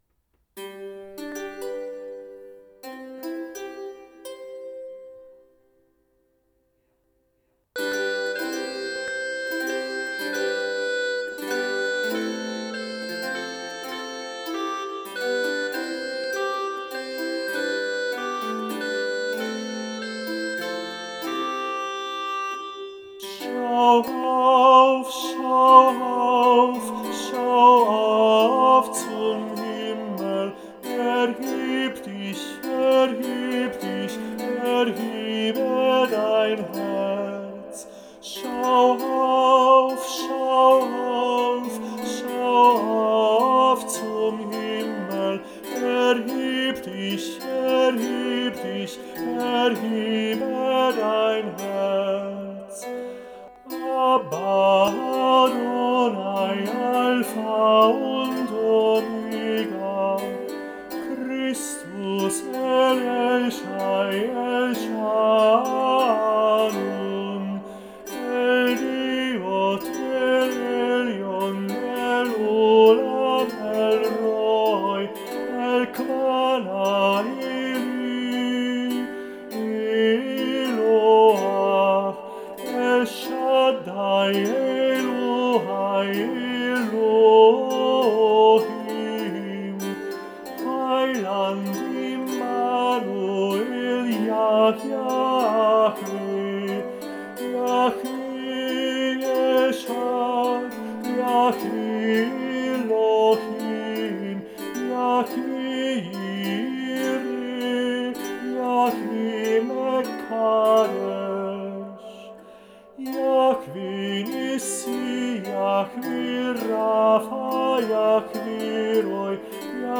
Es beginnt der Engel das Lied mit einem Blasinstrument.
Es handelt sich dabei um eine Schalmel (Martinstrompete) Der Gesang wird nur von der Laute begleitet. Zum Intro sowie zwischen den Ges�ngen und zum Ende des Liedes erklingt die Schalmel. Der Text wird von den Heiligen Engeln mehrstimmig gesungen, die Anrufungen des Namen Gottes einstimmig.